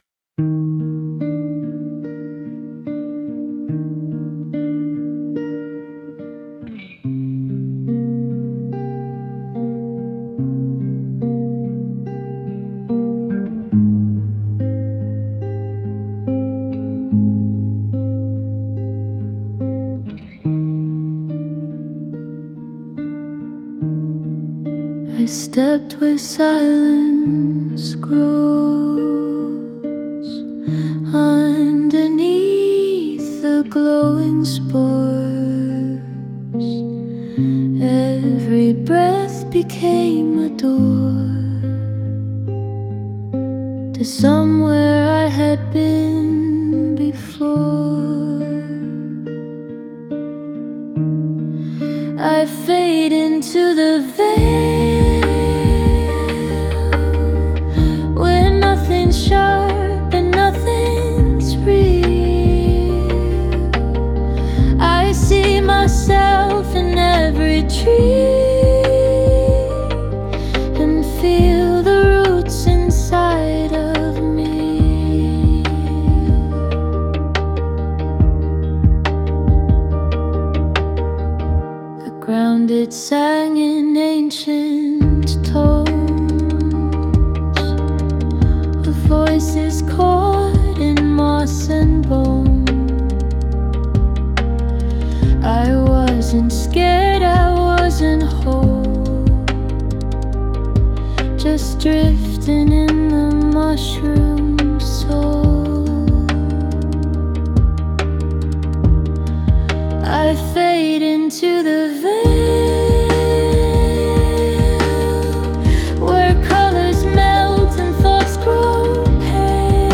A humid stillness you can almost hear.
Distant echoes. Breathing spores.